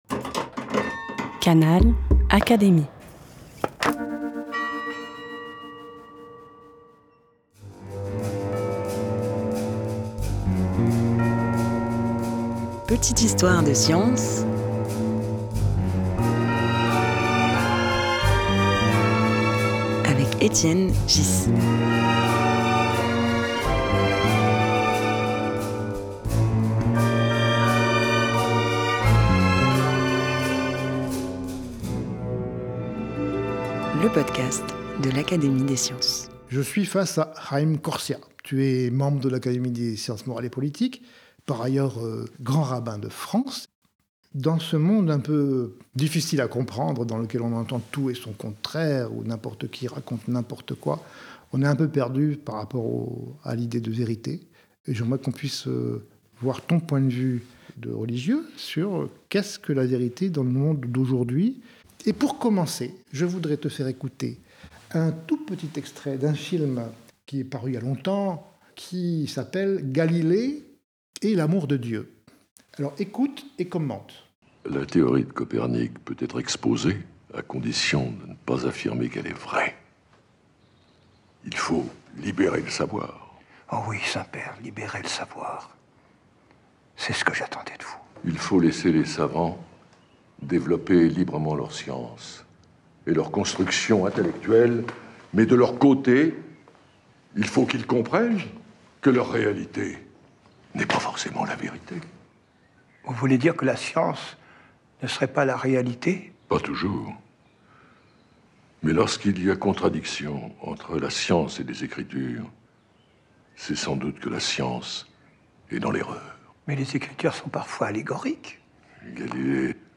Un podcast animé par Étienne Ghys, proposé par l'Académie des sciences.